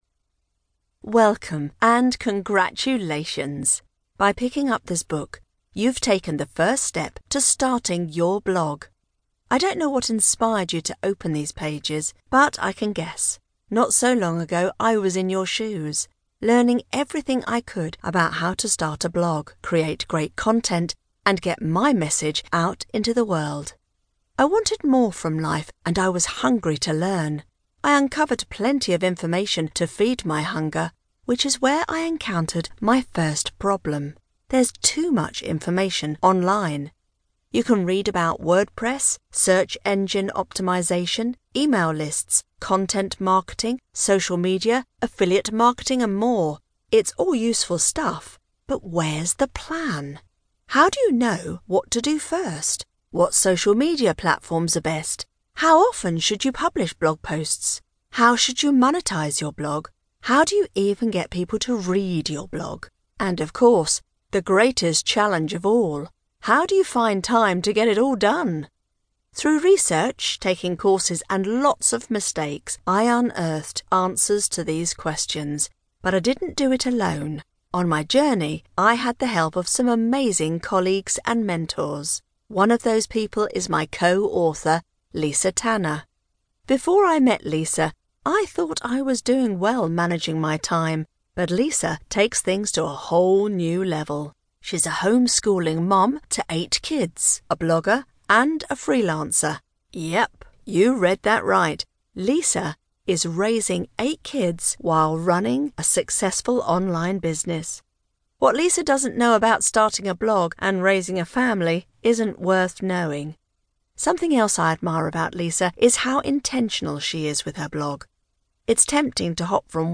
Audiobook Narration